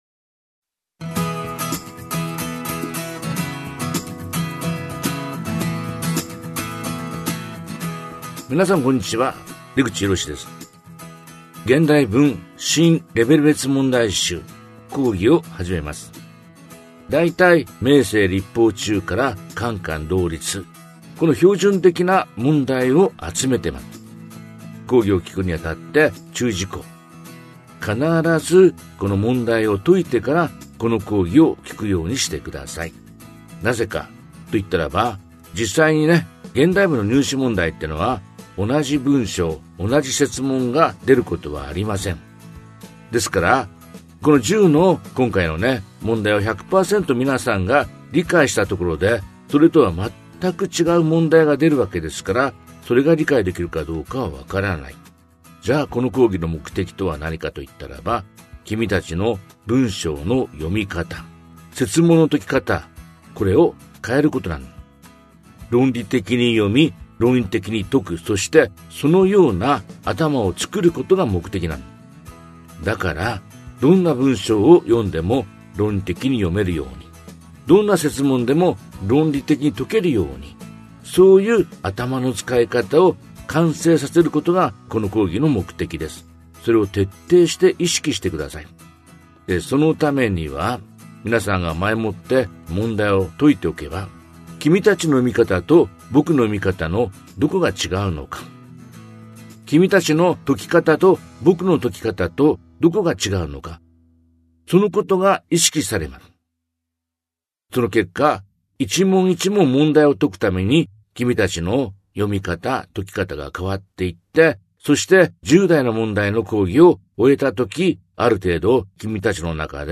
[オーディオブック] 出口式 現代文 新レベル別問題集 3 標準編
長年、数々の大手予備校において名物現代文講師として実績ある指導をしてきた、そのキャリアに裏打ちされた問題解読のための要点を掴む、唯一無二の方法を、出口氏自らの音声講義で聴きながら解いて学べる問題集。